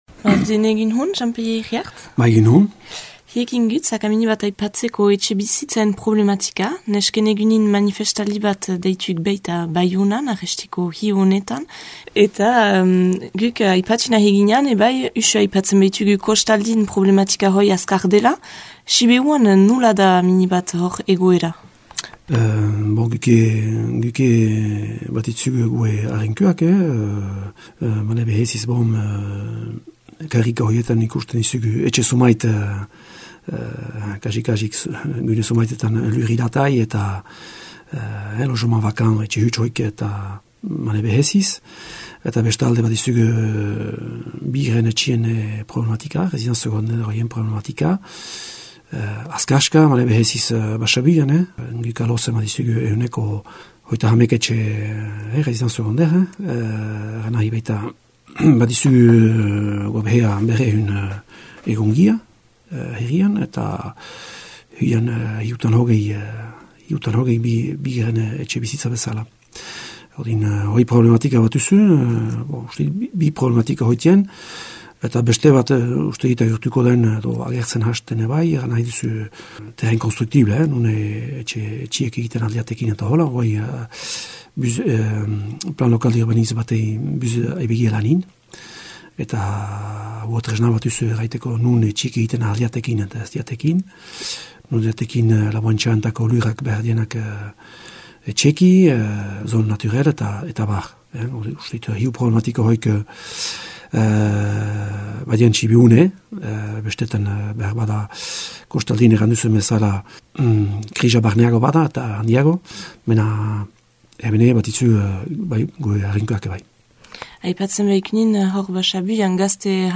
Manifestaldia aitzaki gisa hartürik, Jean Pelle Iriart Aloze Ziboze Omizeko merarekilan mintzatü gira.